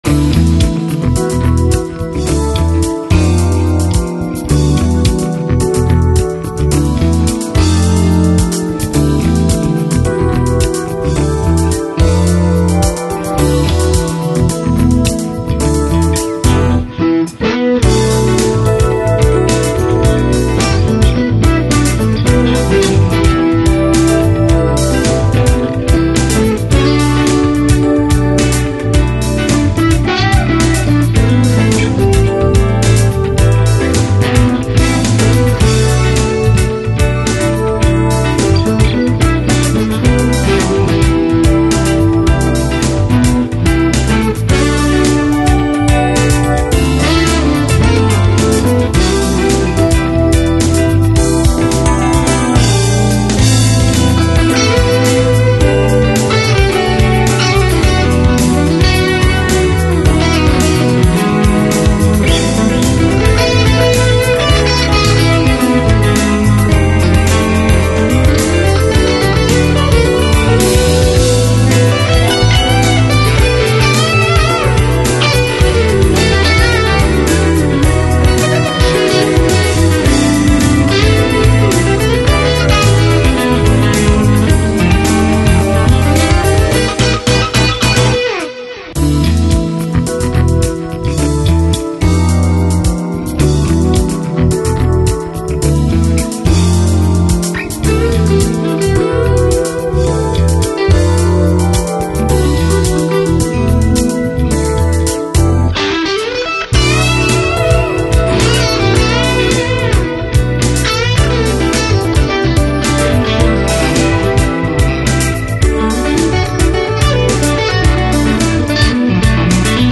minor
とても渋め　アーバン風？